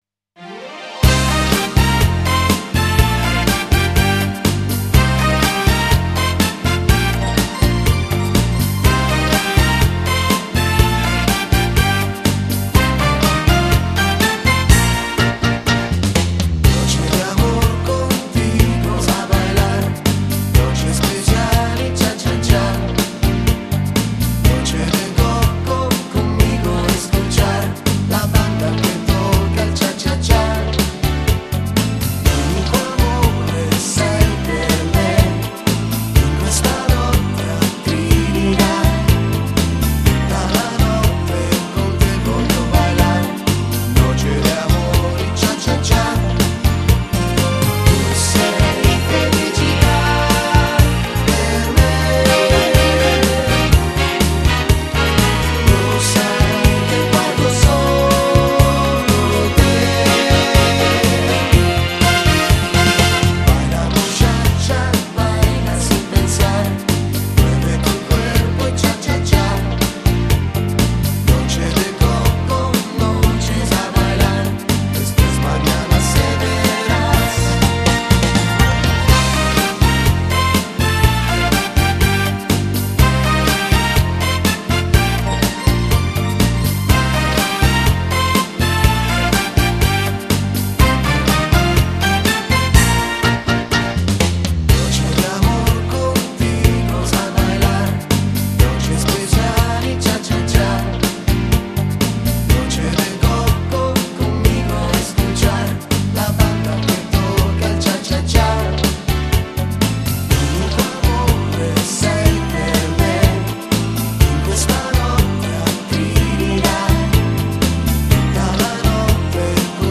Genere: Cha cha cha